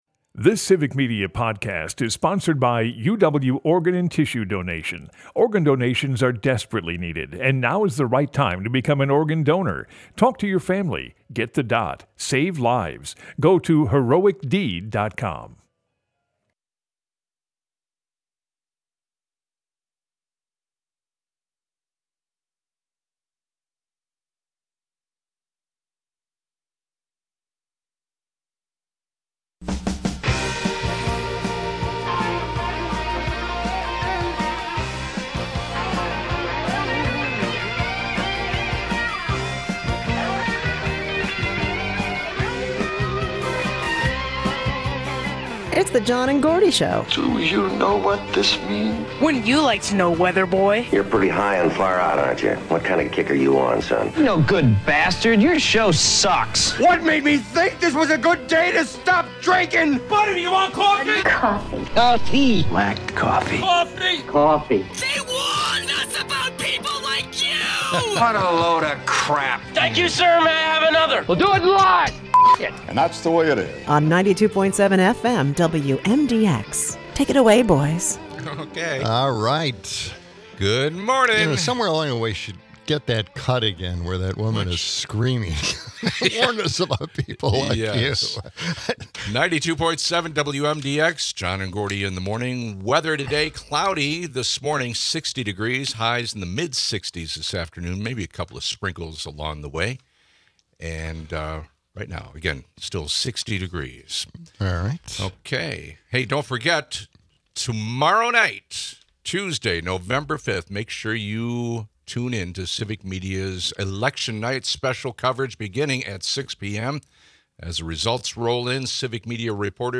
We hear more clips from her and Tim Walz on the stump, discussing Gen Z voters, abortion rights, and more.